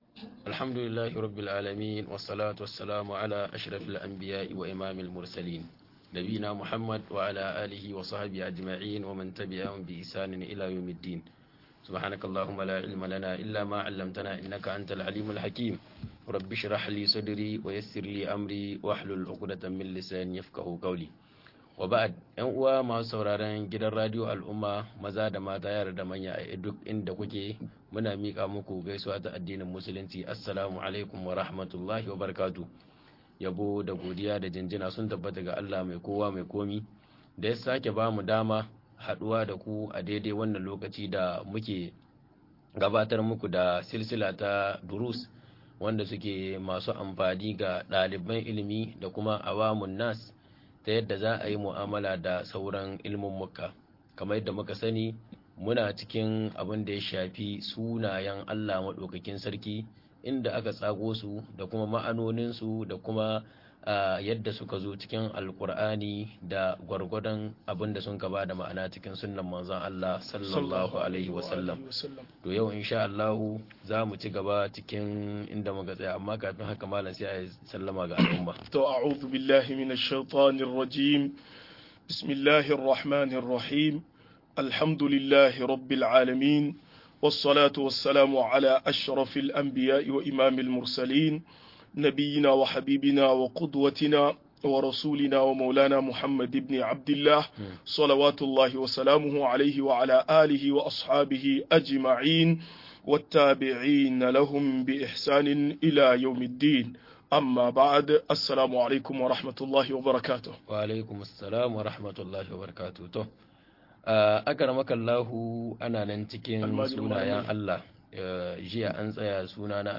Sunayen Allah da siffofin sa-21 - MUHADARA